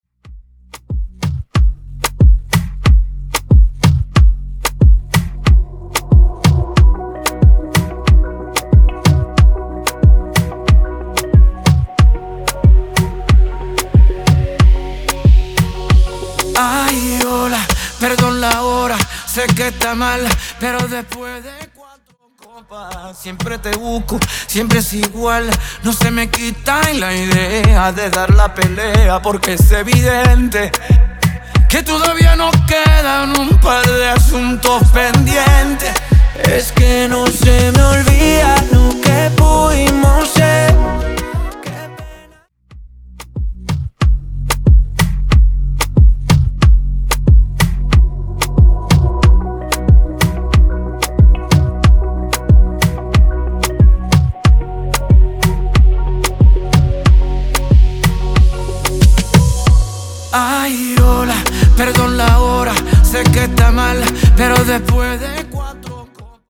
Extended Dirty Intro Acapella, Intro